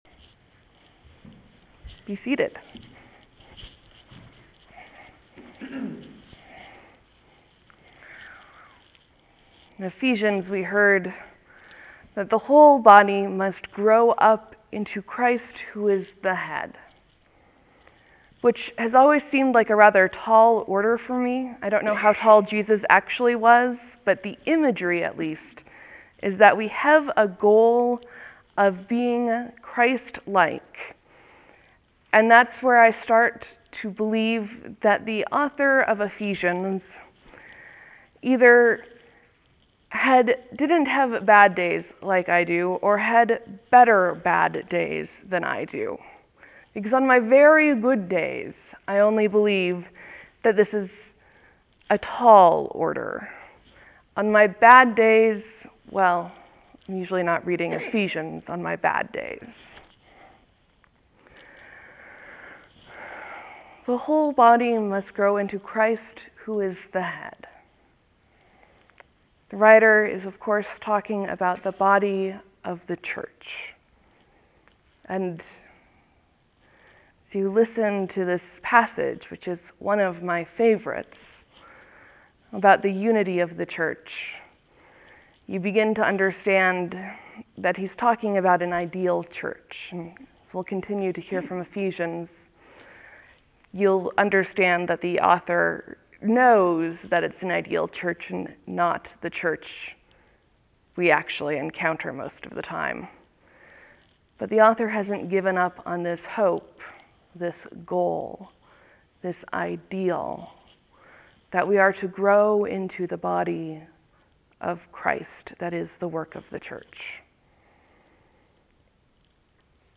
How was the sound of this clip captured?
There will be a few moments of silence before the sermon starts.